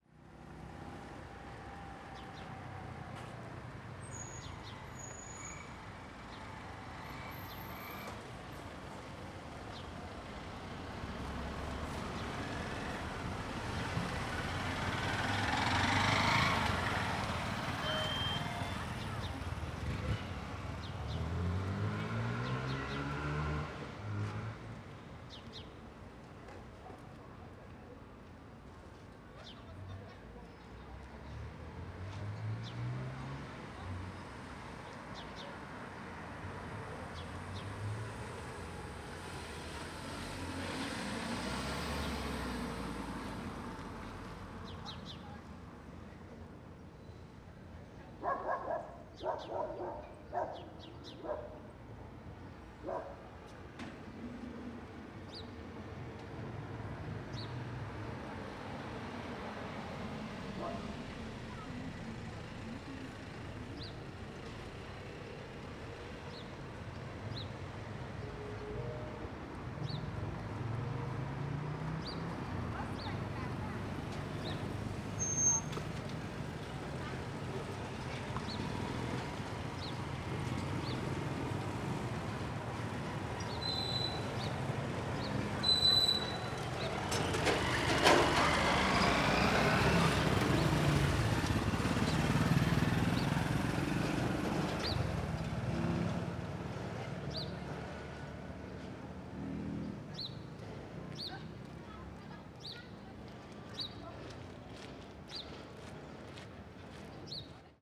CSC-18-059-OL- Rua comercial de cidade pequena com transito.wav